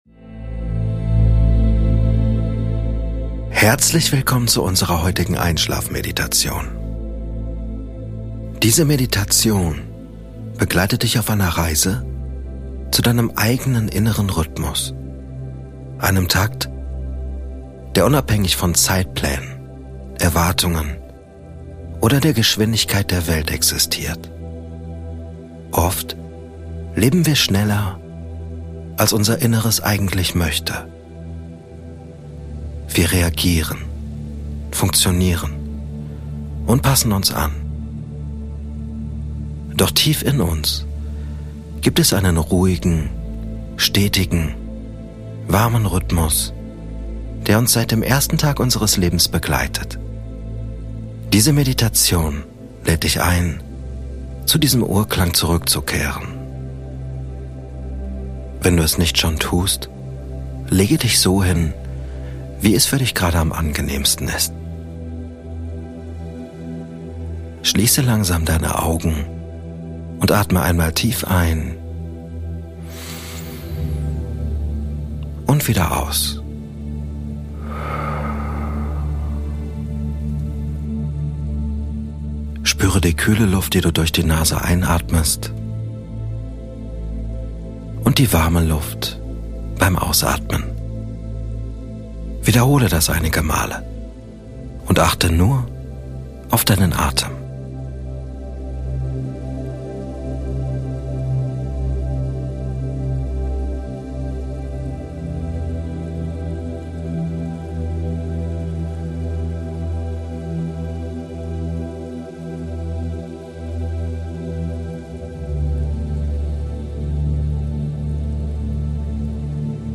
In dieser Folge erwartet dich eine beruhigende Einschlafmeditation, die dich zurück zu deinem eigenen inneren Rhythmus führt.
Durch die Kombination aus Achtsamkeit, ruhiger Atemführung und einem tiefen Bodyscan sinkst du Schritt für Schritt in einen Zustand körperlicher und geistiger Entspannung. Die sanfte Sprache, die meditativen Bilder und die klare Ausrichtung auf Stressabbau unterstützen dein Nervensystem dabei, vom schnellen Rhythmus des Tages in einen erholsamen, natürlichen Takt umzuschalten.